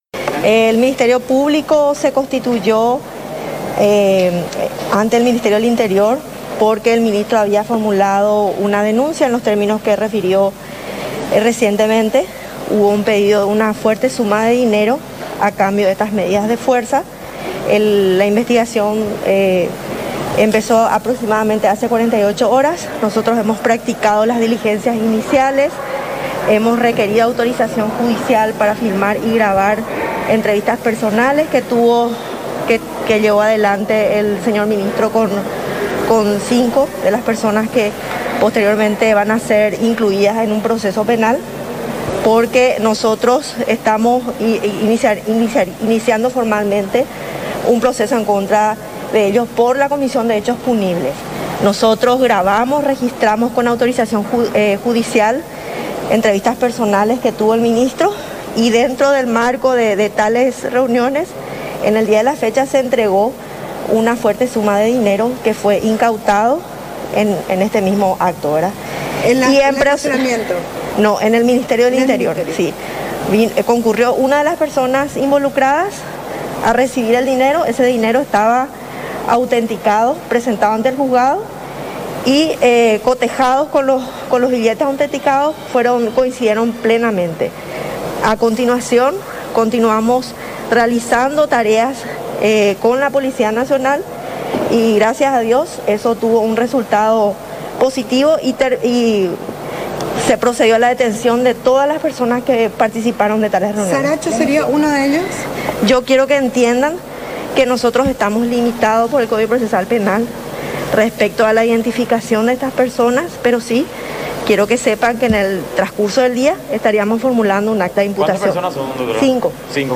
La fiscala Liliana Alcaraz informó este miércoles en conferencia de prensa que cuatro personas quedaron detenidas por solicitar 50.000 dólares para levantar el paro de camioneros que se realiza en la jornada.